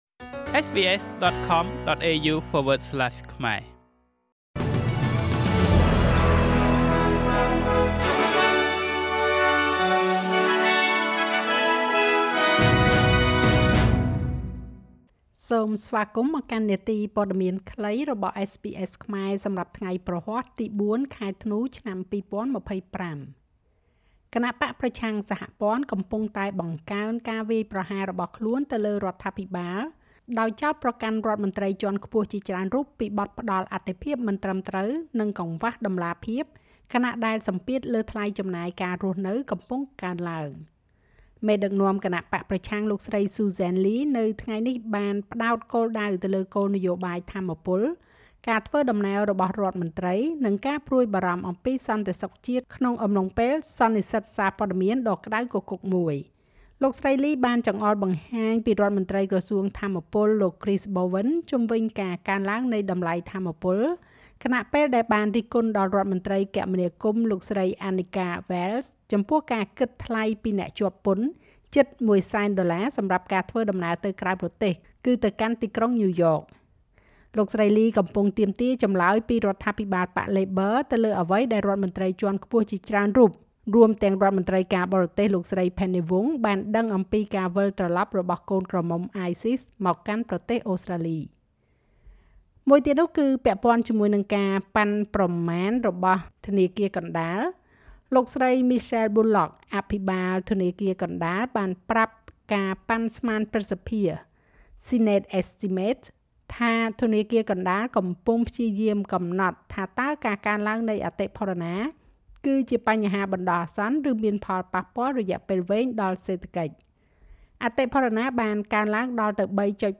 នាទីព័ត៌មានខ្លីរបស់SBSខ្មែរសម្រាប់ថ្ងៃព្រហស្បតិ៍ ទី៤ ខែធ្នូ ឆ្នាំ២០២៥